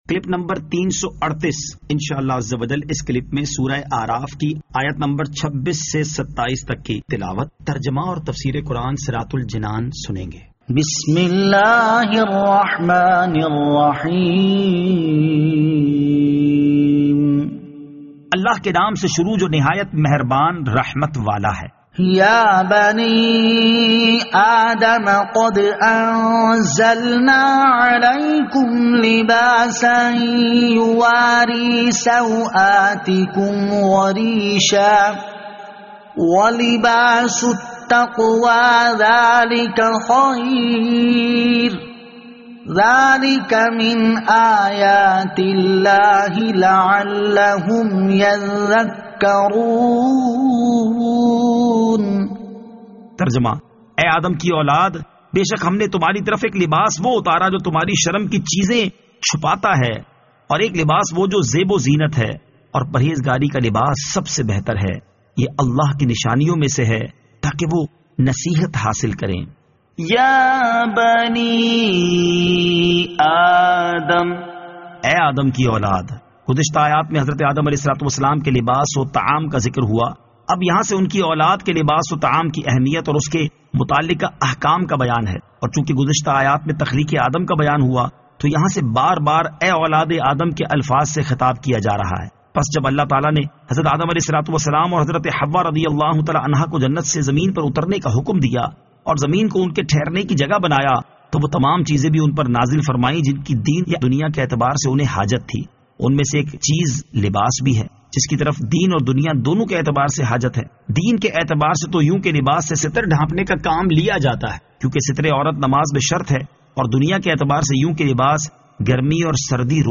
Surah Al-A'raf Ayat 26 To 27 Tilawat , Tarjama , Tafseer